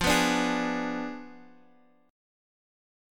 Fdim7 chord